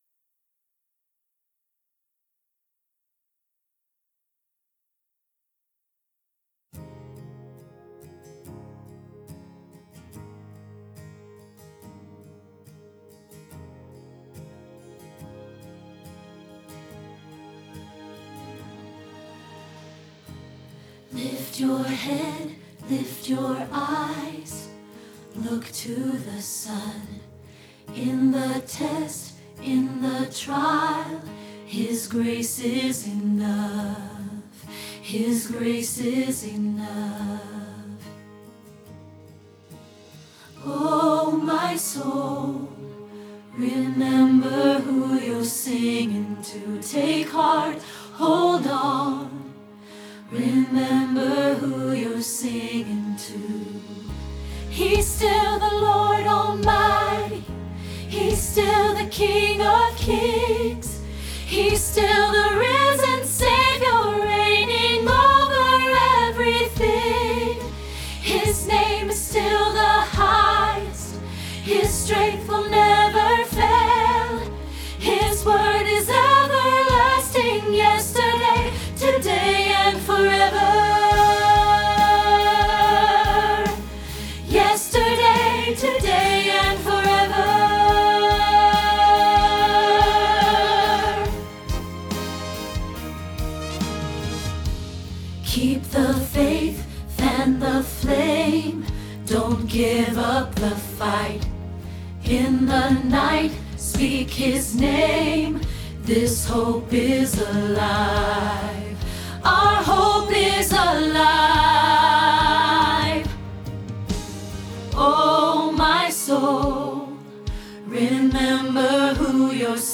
Yesterday, Today, Forever – Soprano – Hilltop Choir